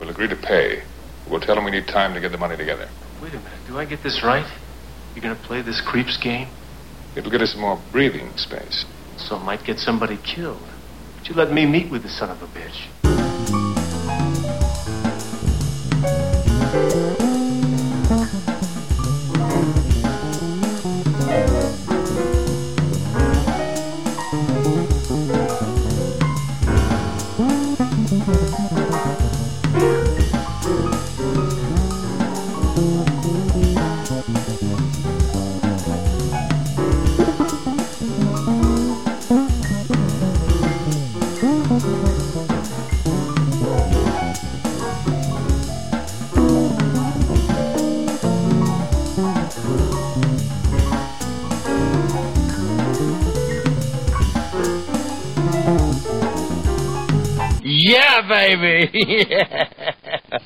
Tags: silly nutty weird noise sound collage